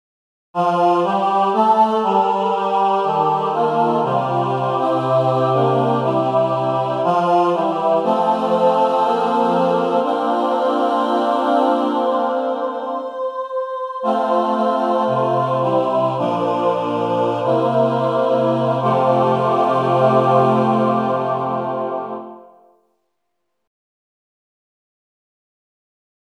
Key written in: F Major
How many parts: 6
Type: SATB
All Parts mix: